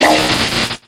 Cri d'Armulys dans Pokémon X et Y.